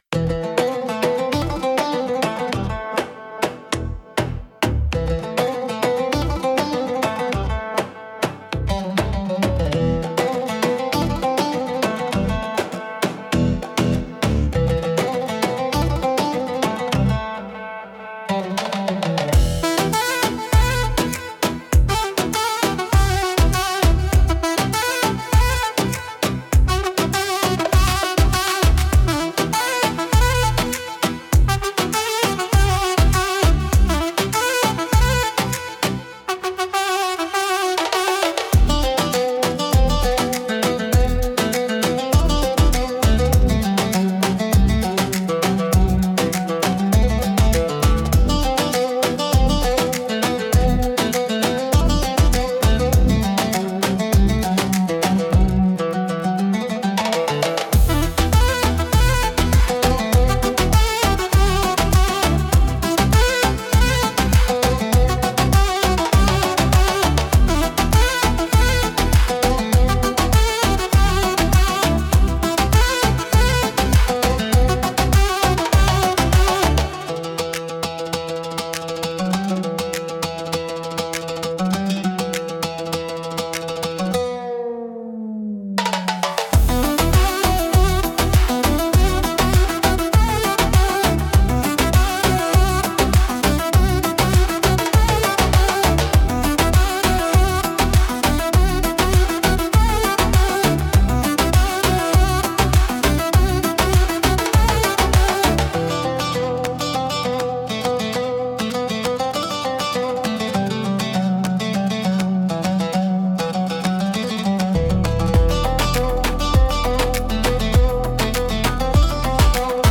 ウードやカヌーン、ダラブッカなどの民族楽器が使われ、神秘的でエキゾチックな雰囲気を醸し出します。
独特のメロディとリズムで聴く人の感覚を刺激します。